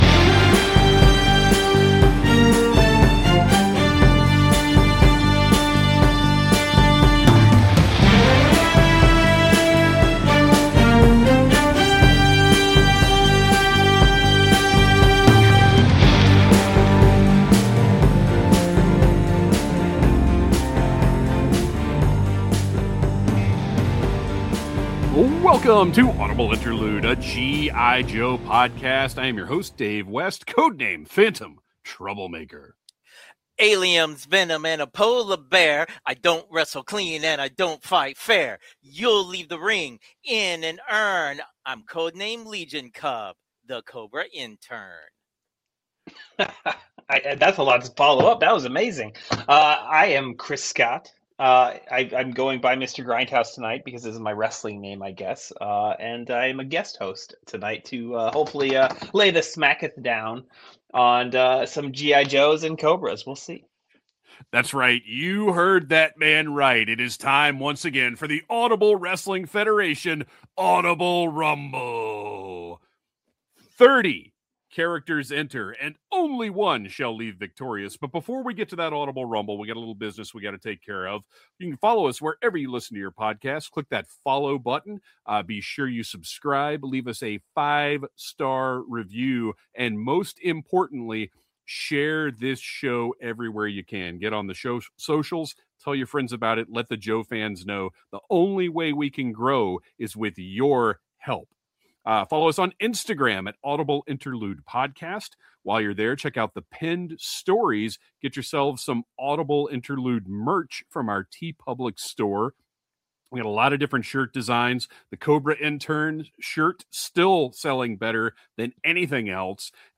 We're recruiting you to listen to this podcast hosted by three lifelong Joe fans.